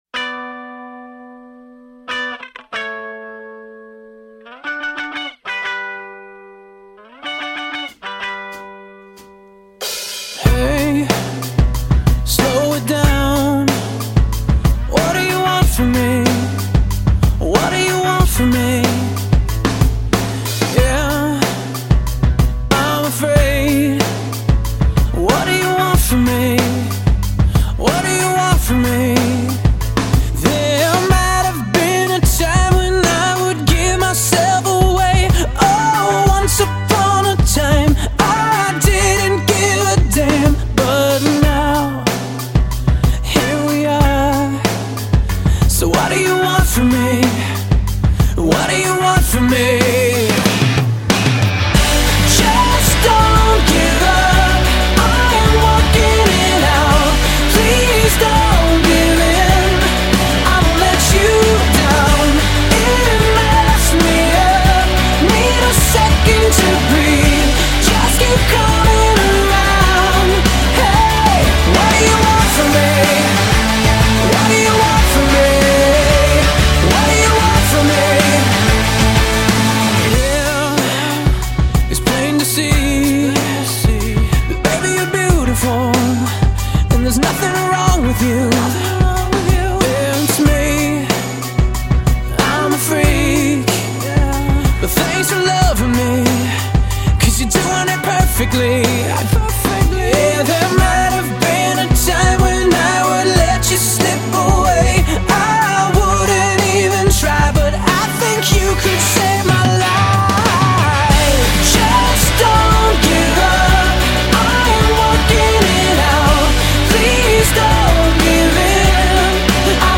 慢搖酒吧